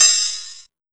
Index of /90_sSampleCDs/USB Soundscan vol.20 - Fresh Disco House I [AKAI] 1CD/Partition D/01-HH OPEN